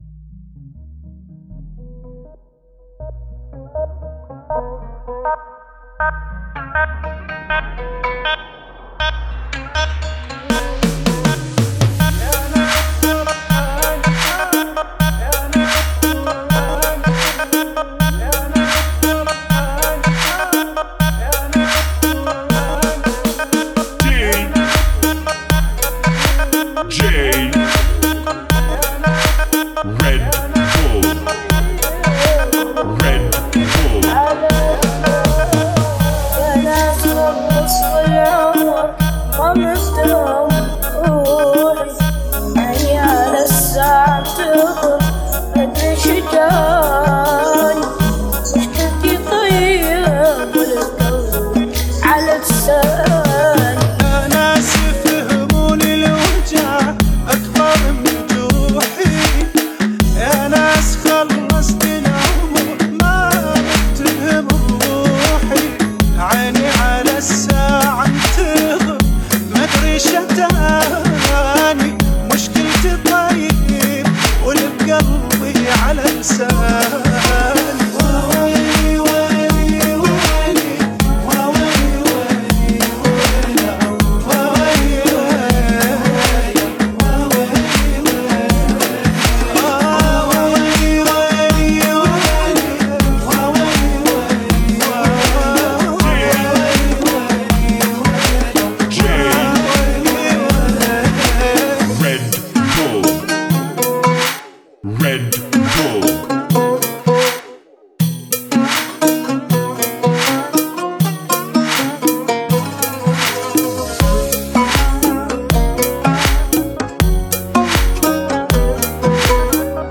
80 bpm